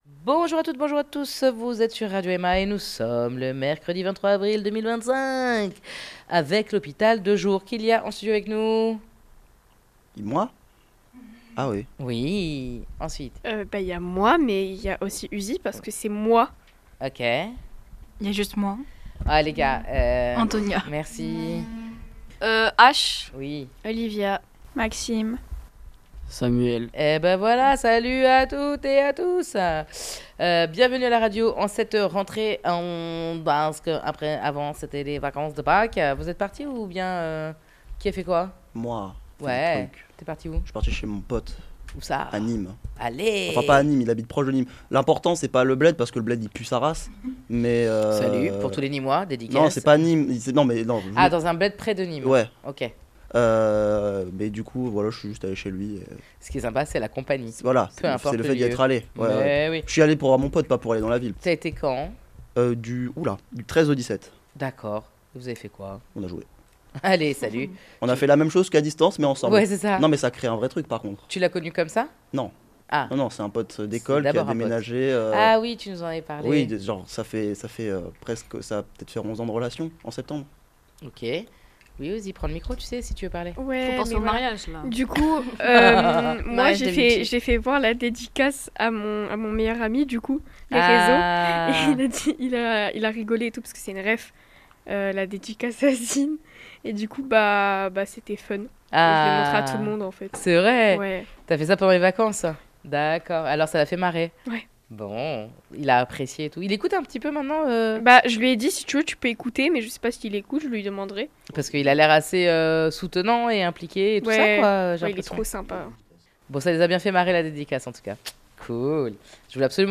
Dans cette émission survoltée, comme d'hab', nous parlons de nos sens les plus développés et de ceux qui le sont moins. Mais aussi de la façon dont nos sens impactent nos vies et nos fonctionnements.